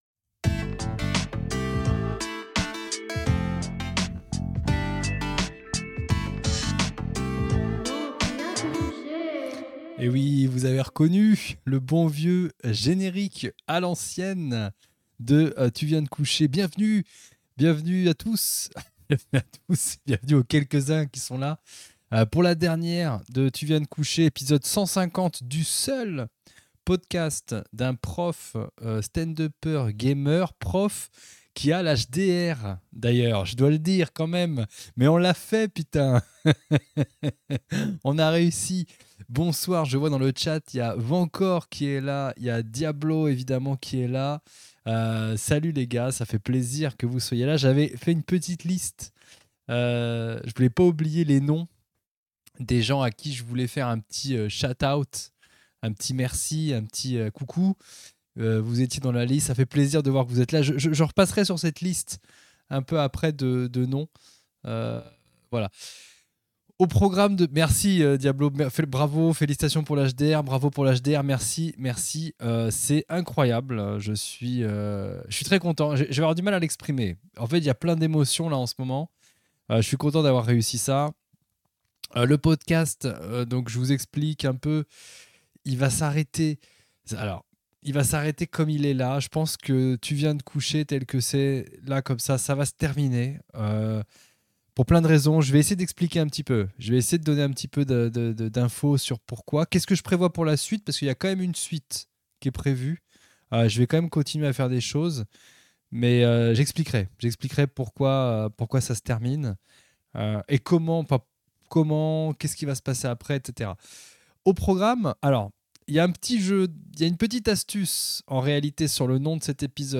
en Live sur Twitch les samedis soirs.